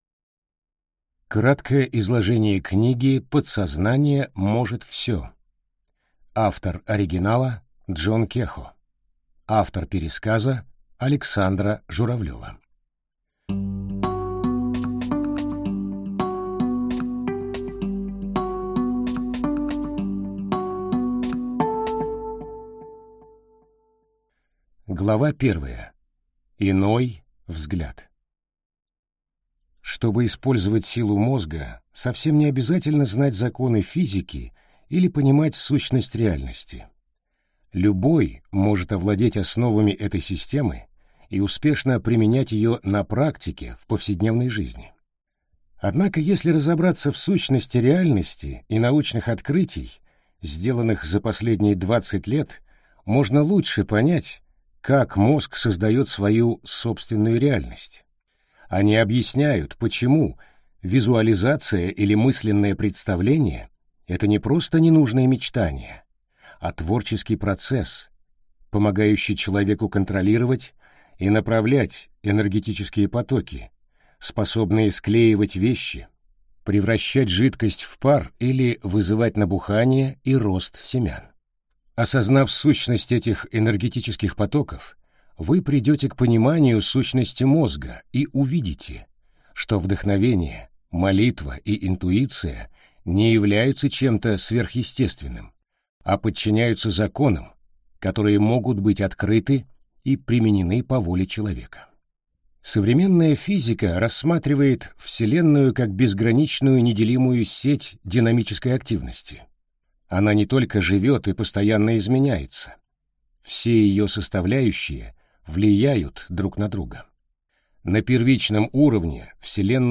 Аудиокнига Краткое изложение книги «Подсознание может всё!».